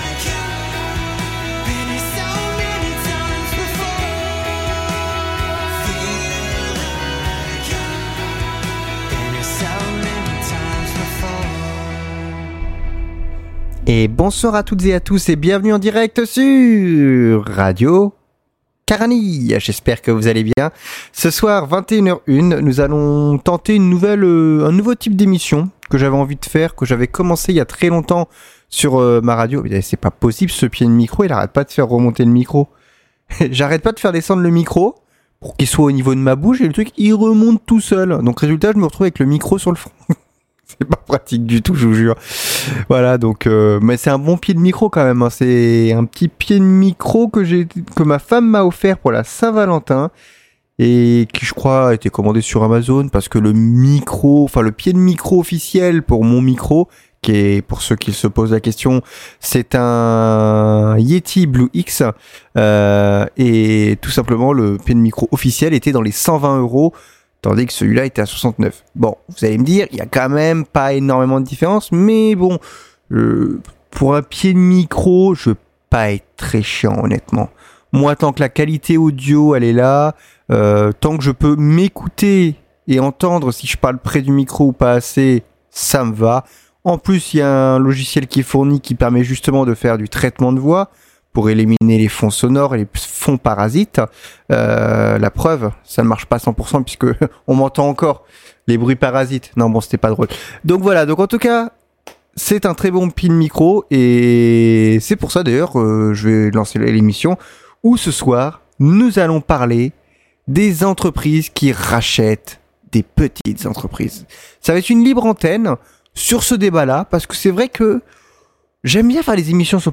L’émission de ce soir sera accès sur un thème de libre antenne pour faire un essai et voir vos retours